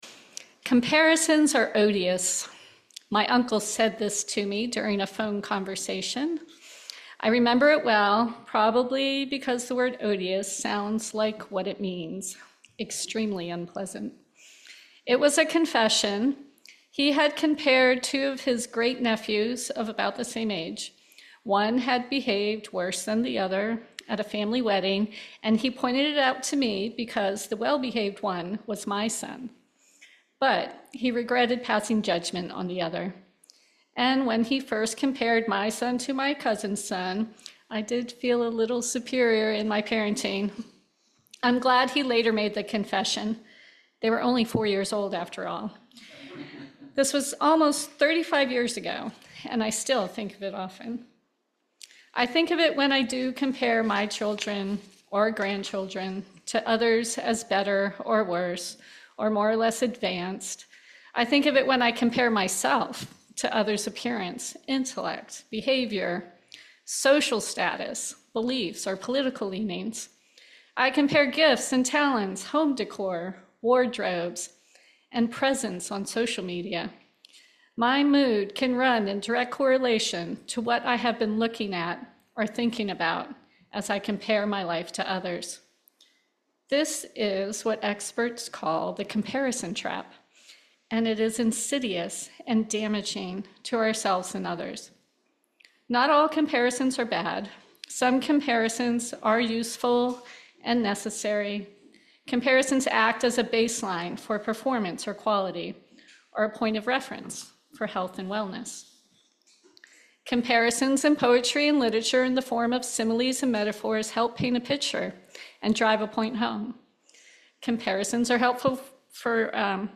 This sermon explores the pervasive nature of social comparison, describing how measuring ourselves against others often leads to a “trap” of judgment, superiority, or self-condemnation